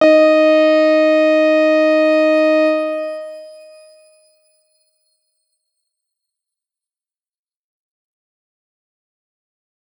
X_Grain-D#4-pp.wav